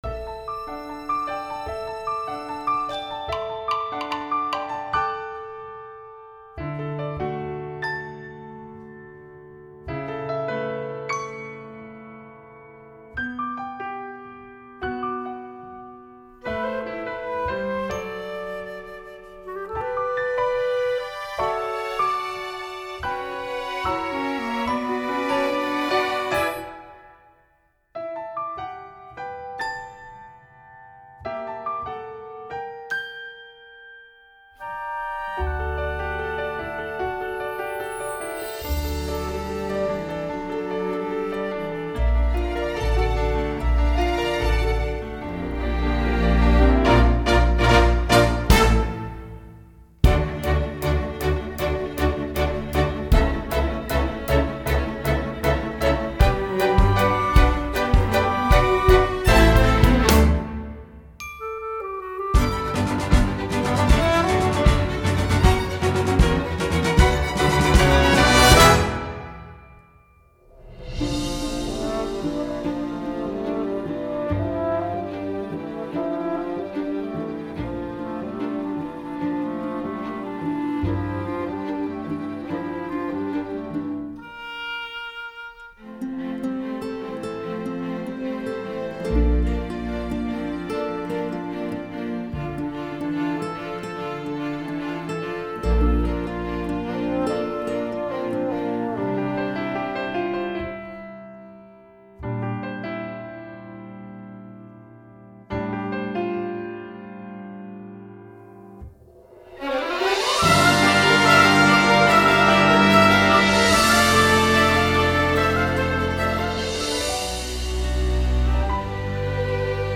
Song with lyrics